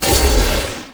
Magic_SpellImpact01.wav